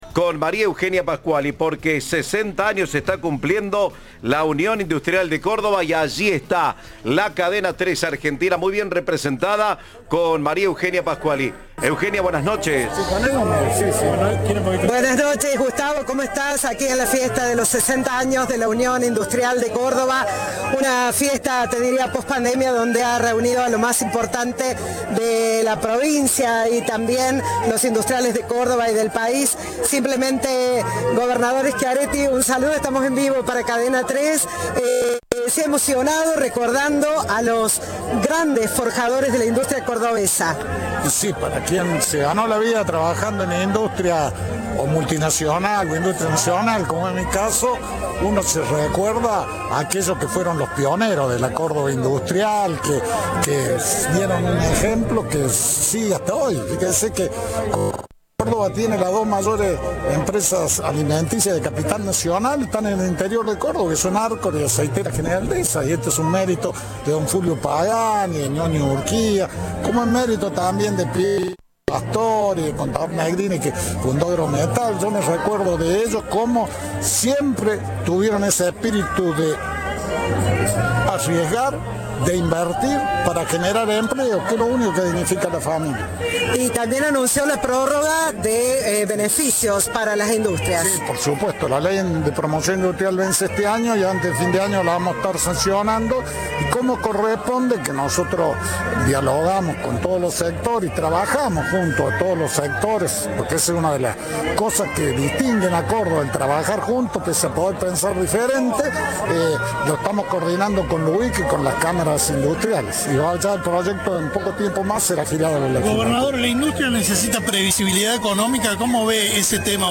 Schiaretti participó de la fiesta por el 60 aniversario de la Unión Industrial de Córdoba (UIC), que se hizo en el Salón Avril de la capital cordobesa.